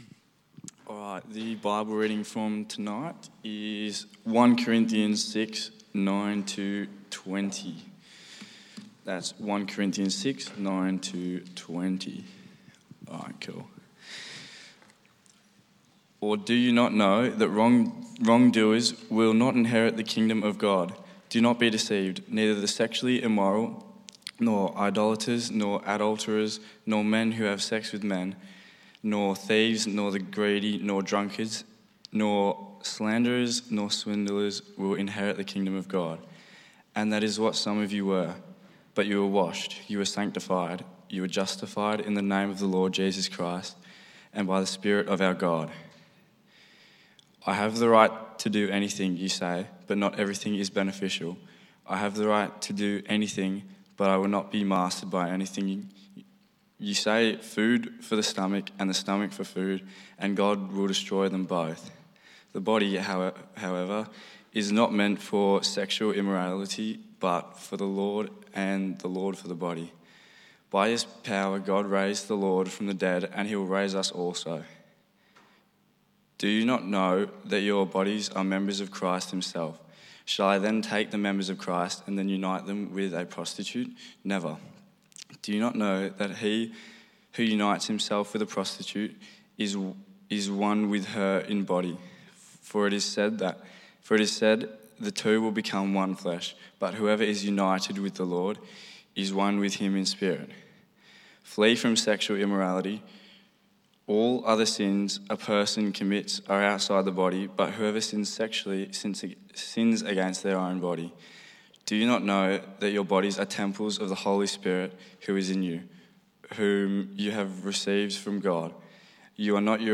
Passage: 1 Corinthians 6:9-20 Service Type: 6PM